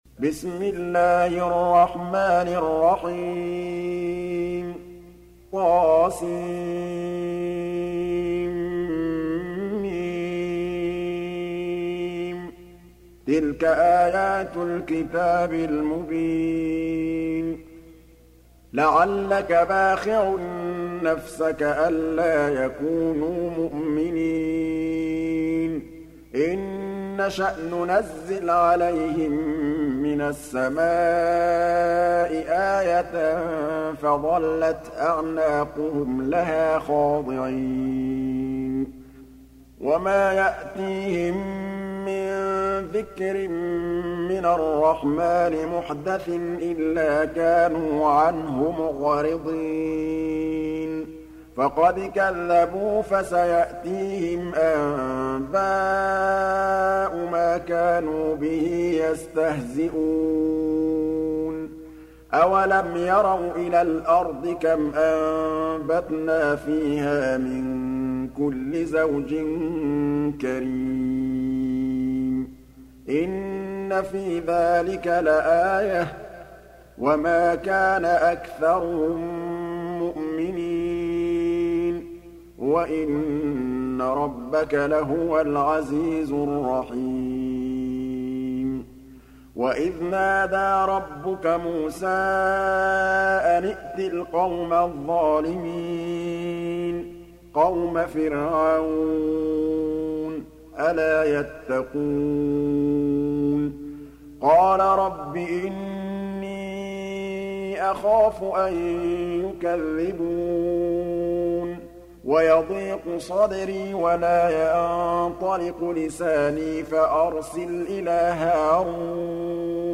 Surah Sequence تتابع السورة Download Surah حمّل السورة Reciting Murattalah Audio for 26. Surah Ash-Shu'ar�' سورة الشعراء N.B *Surah Includes Al-Basmalah Reciters Sequents تتابع التلاوات Reciters Repeats تكرار التلاوات